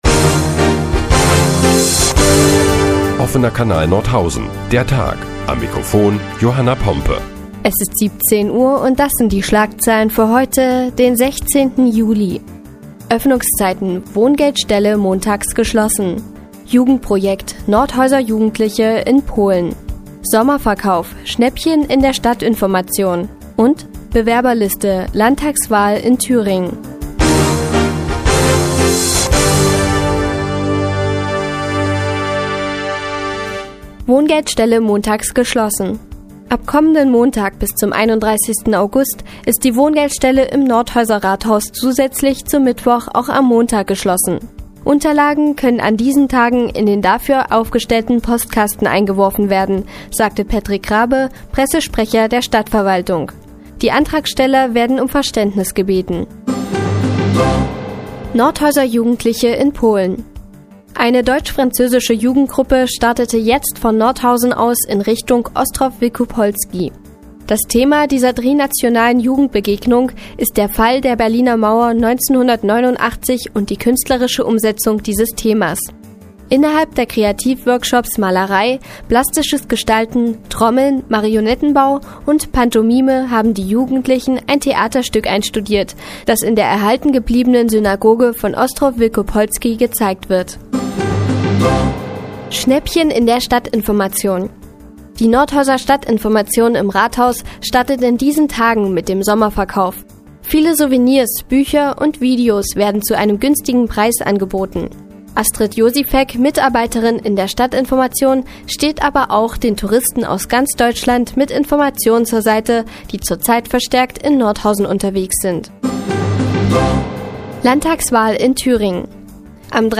Die tägliche Nachrichtensendung des OKN ist nun auch in der nnz zu hören. Heute geht es unter anderem um Nordhäuser Jugendliche in Polen und die Landtagswahl in Thüringen.